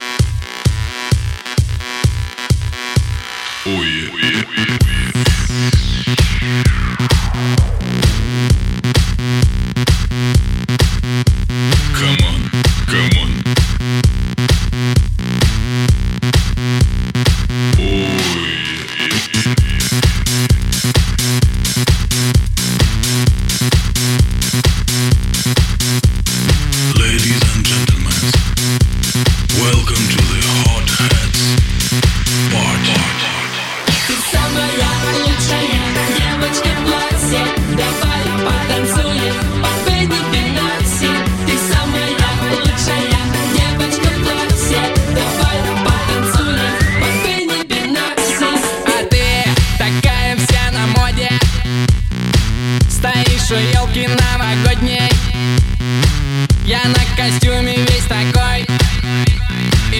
Pop
Лучшие танцевальные треки наступающего сезона!